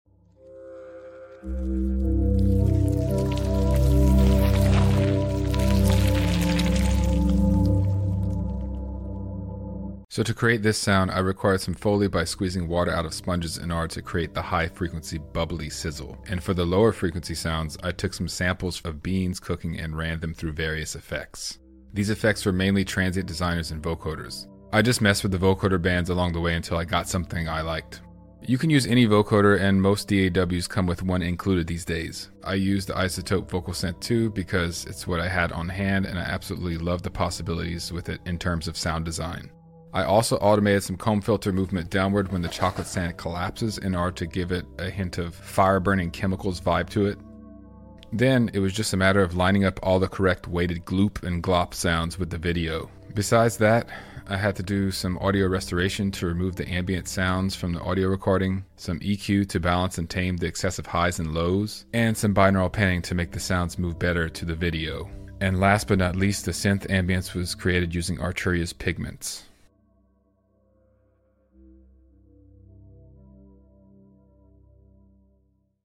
Here is a quick explanation of how I made this melting goop sound.